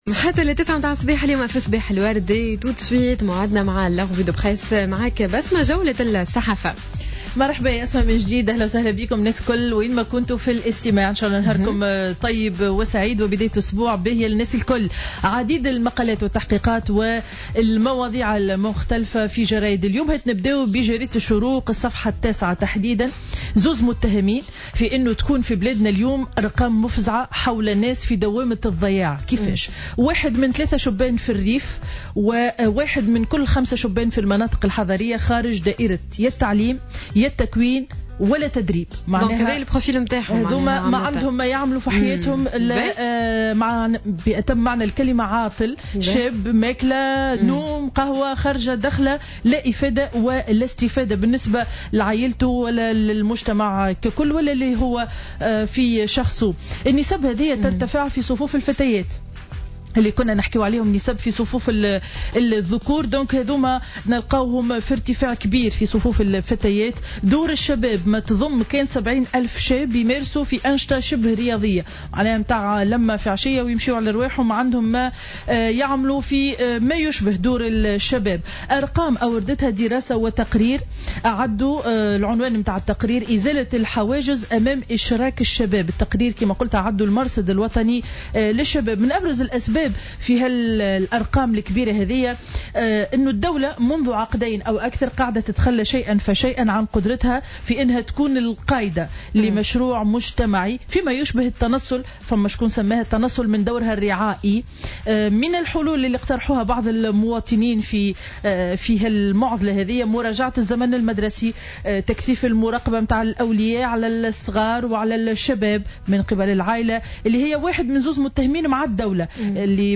معرض الصحافة ليوم الاثنين 20 أفريل 2015